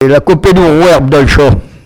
Olonne-sur-Mer
locutions vernaculaires